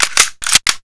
k98_singleshotreload.wav